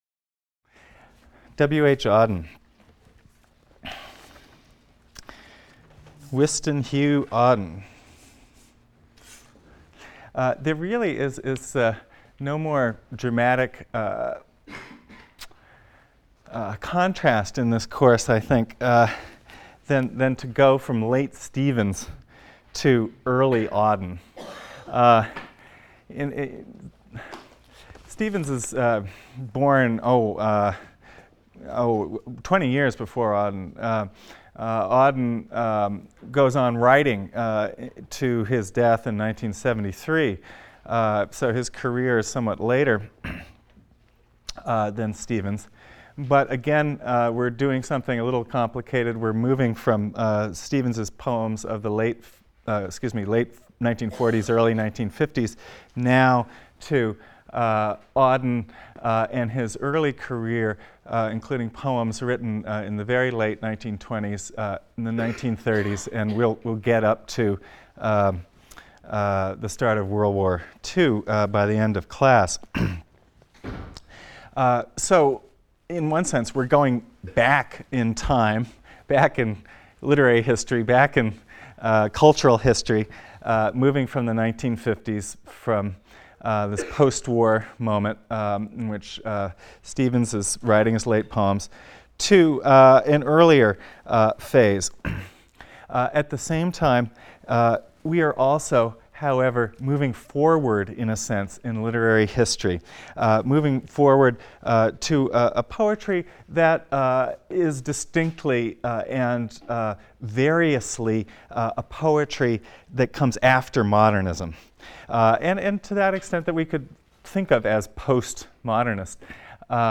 ENGL 310 - Lecture 22 - W. H. Auden | Open Yale Courses